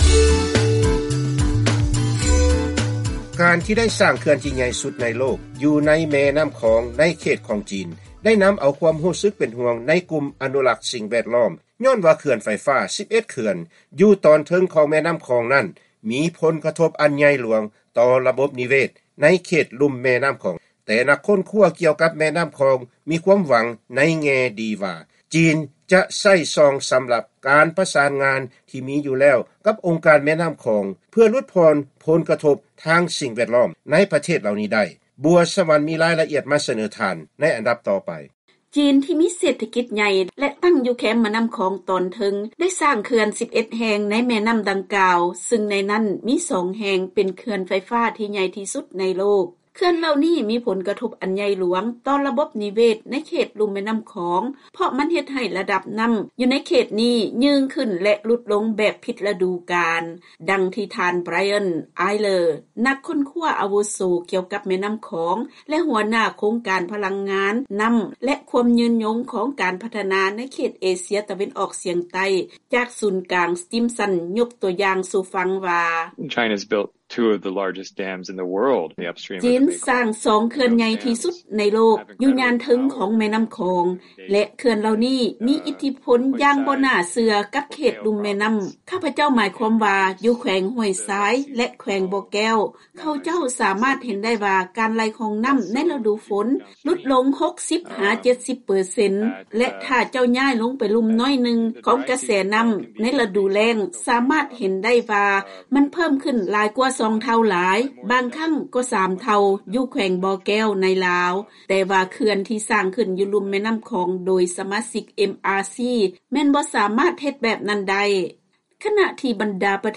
ເຊີນຟັງລາຍງານກ່ຽວກັບຜົນກະທົບຈາກການສ້າງເຂື່ອນ ຢູ່ຕອນເທິງຂອງແມ່ນ້ຳຂອງໂດຍຈີນ ຕໍ່ສະພາບແວດລ້ອມຂອງເຂດລຸ່ມແມ່ນ້ຳ